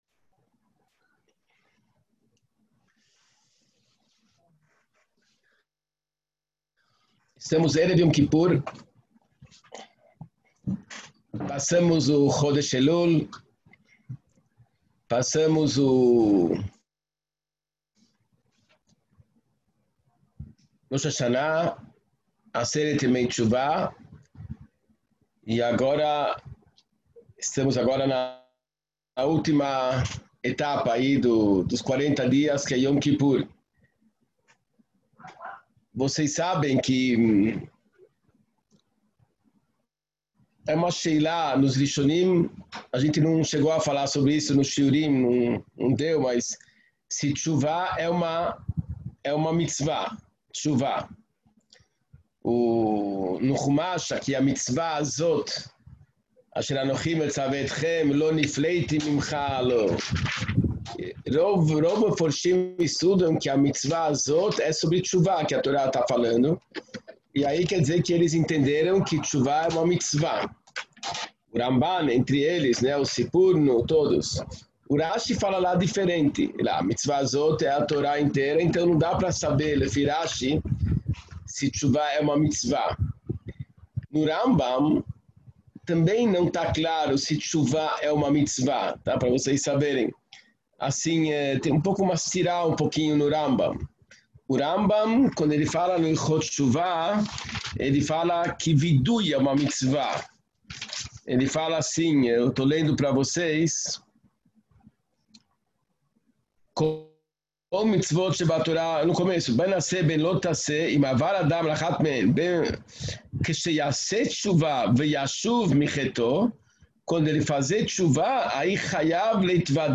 Sichá vespera de Yom Kipur